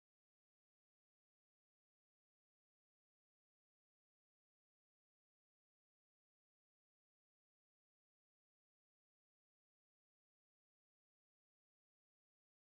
Mural_Pluck.wav